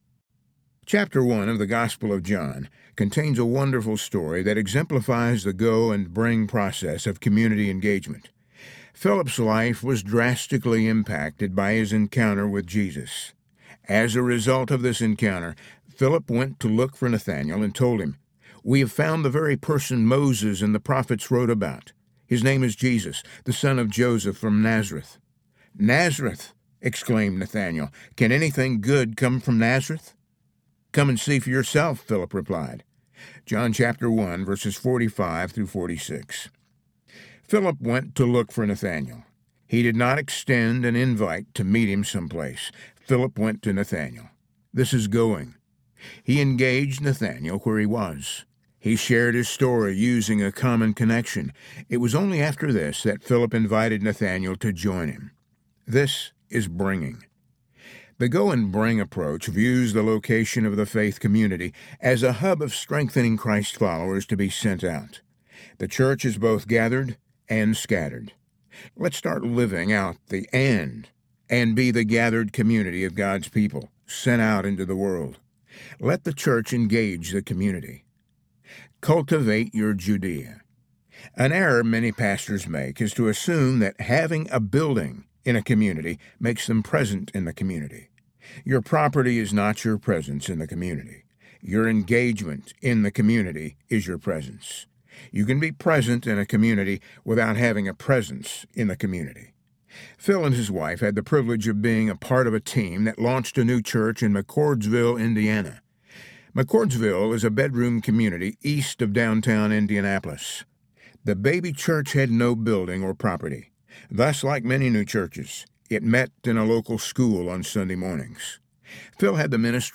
Building the Body Audiobook
5.48 Hrs. – Unabridged